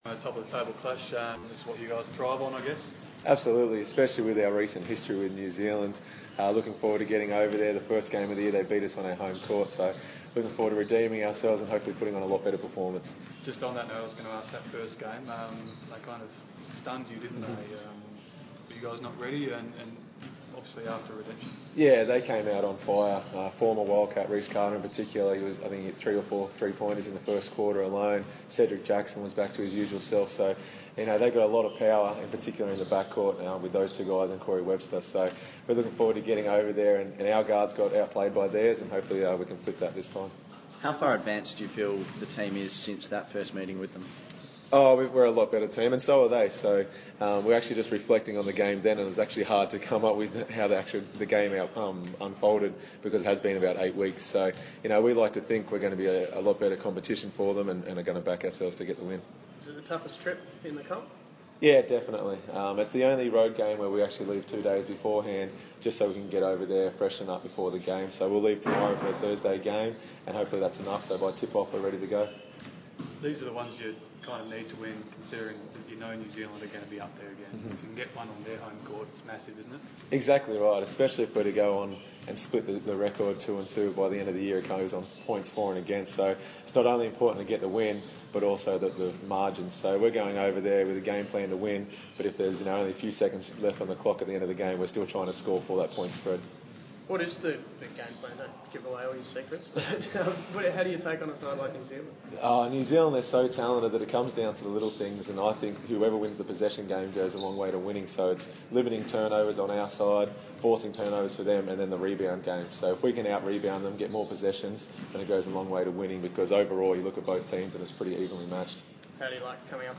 Damian Martin Press Conference - 8 December 2014
Damian Martin speaks to the media ahead of Thursday's top-of-the-table clash with New Zealand.